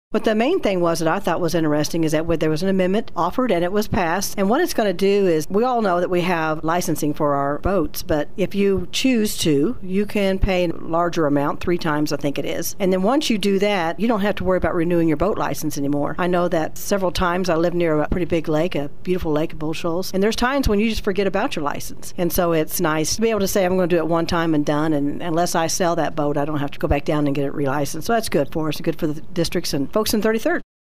State Senator Karla Eslinger talked about a couple of bills this week in her weekly update.